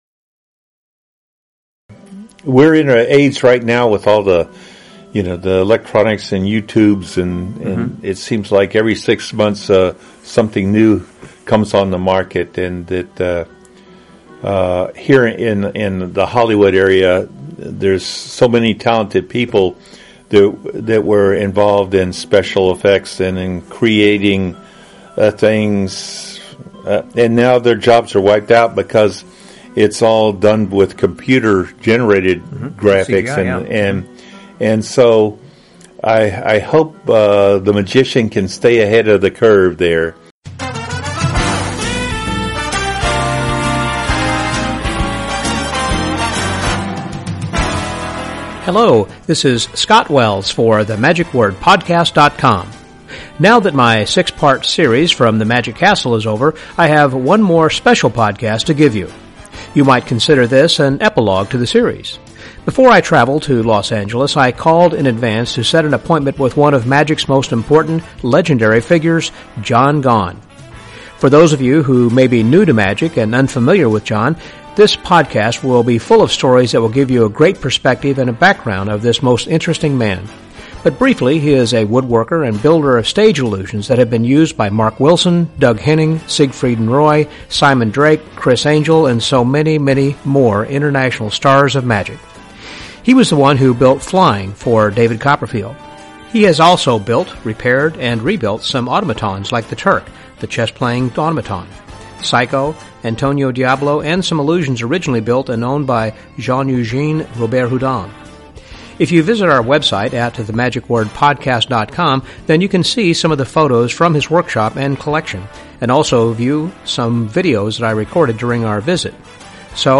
It was such a thrill to spend time in his workshop and magic salon where we talked about magic, magicians past and present, and illusion building.